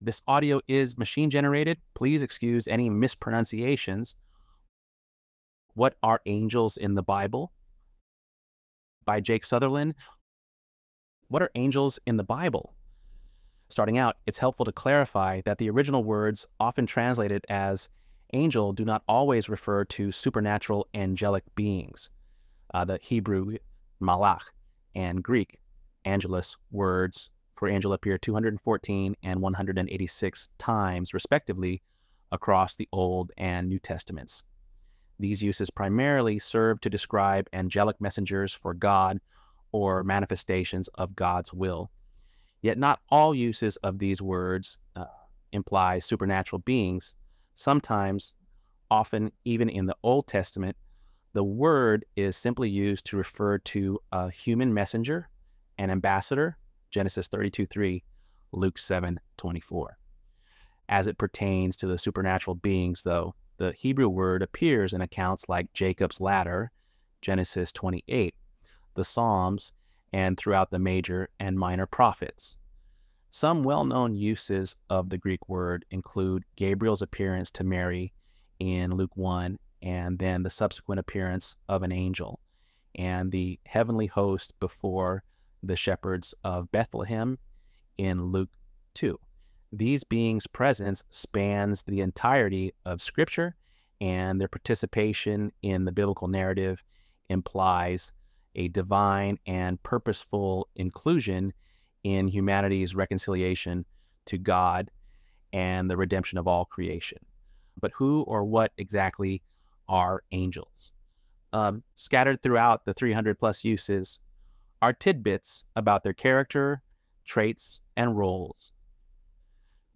ElevenLabs_12_23-1.mp3